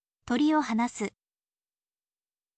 tori o hanasu